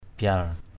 /biʌr/ (d.) bài = carte à jouer.